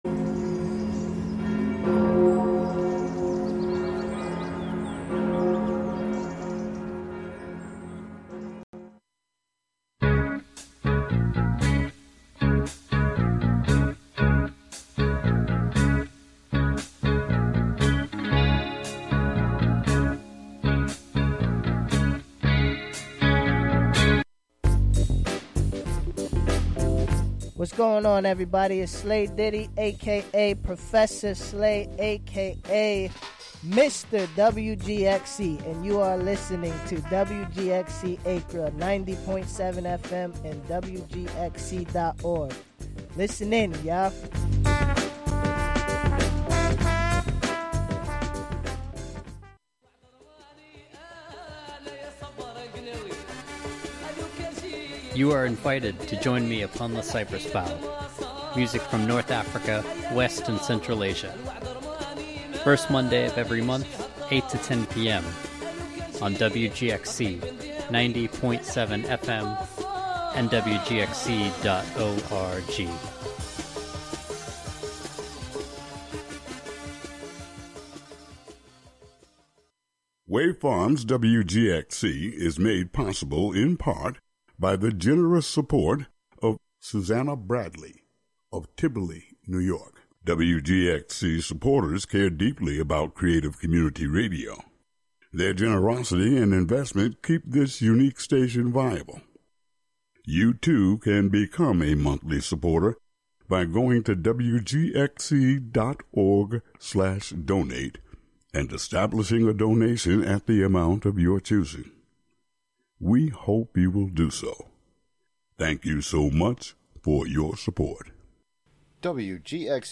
Hosted by youth at Greater Hudson Promise Neighbor...
Hosted by Greater Hudson Promise Neighborhood youths, "The Promise Cool Kidz" is a half hour of talk, rap battles, songs, and more broadcasting live from GHPN!